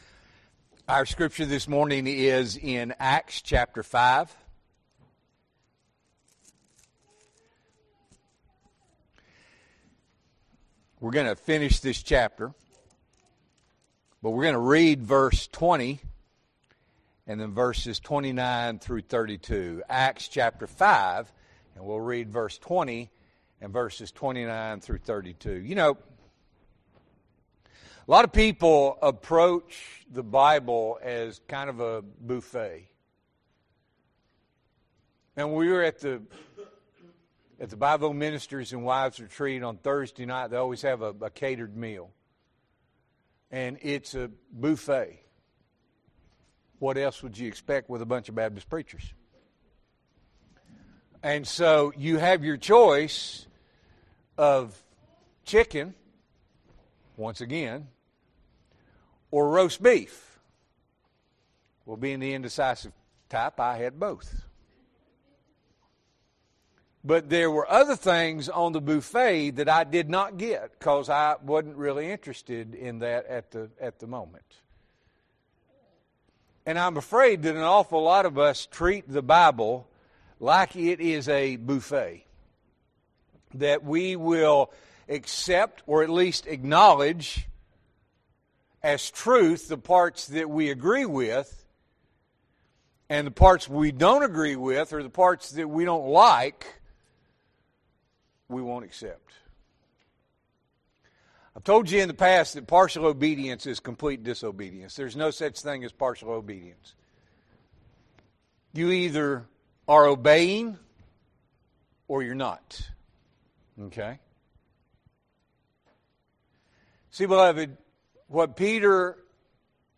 February 8, 2026 – Morning Worship